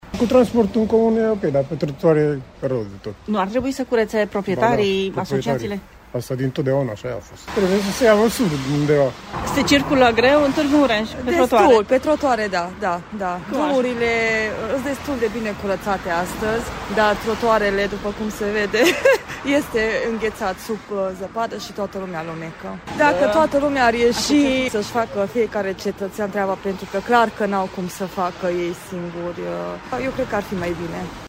Pe trotuare însă e plin de zăpadă și gheață. Proprietarii de imobile, firmele și asociațiile de proprietarii se pare că au uitat să-și curețe trotuarele, spun târgumureșenii: